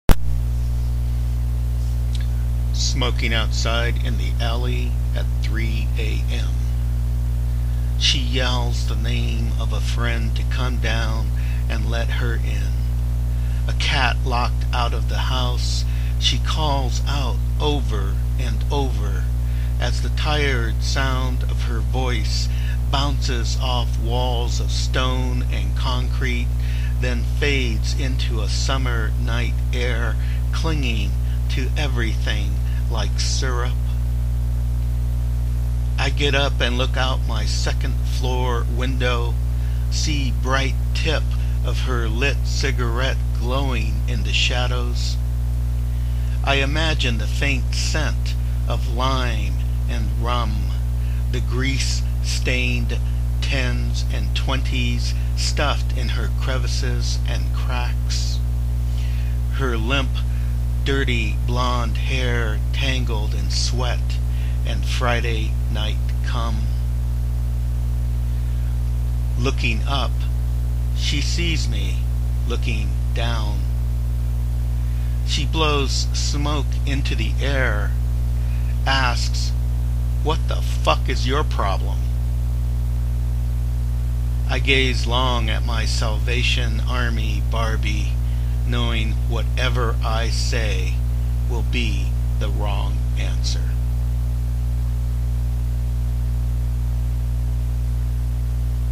read this poem